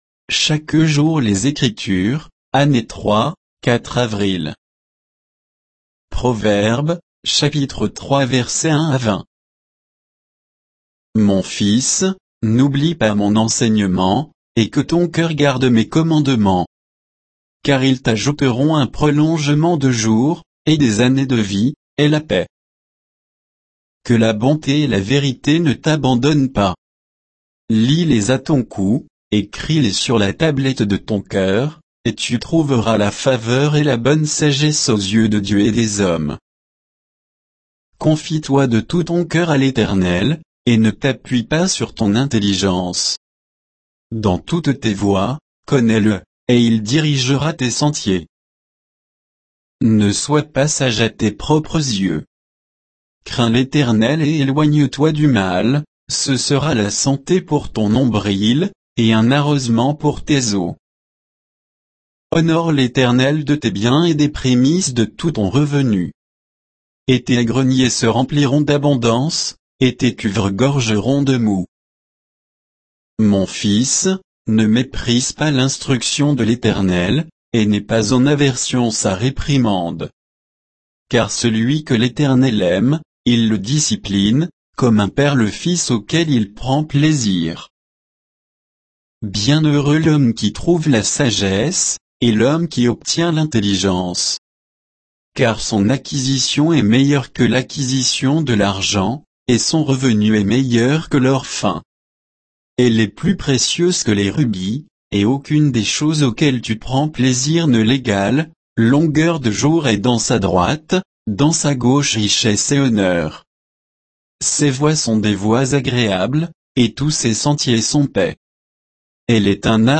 Méditation quoditienne de Chaque jour les Écritures sur Proverbes 3